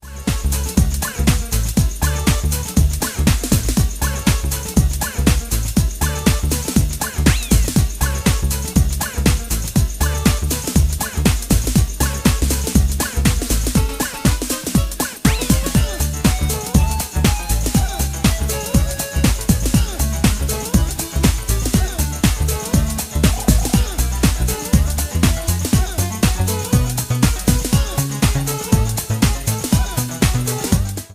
Loopback recorded preview from YouTube.